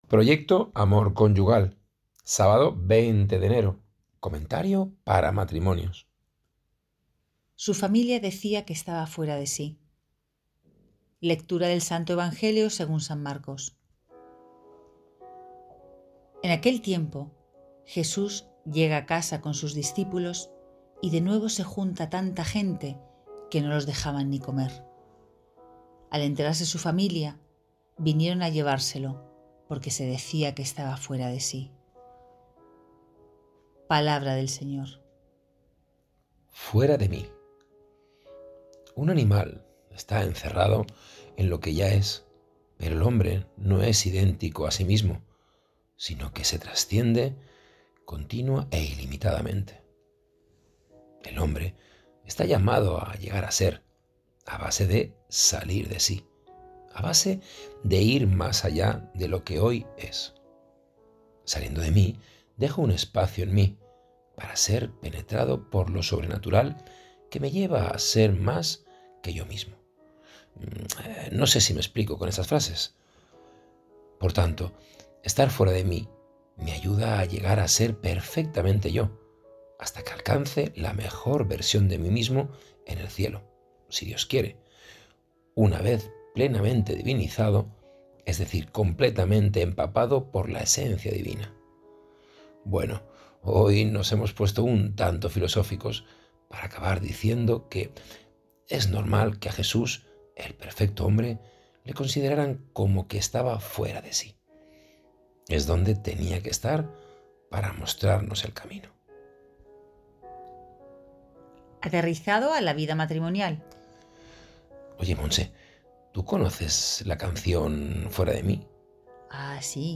Me ha encantado hoy con la canción tatareada.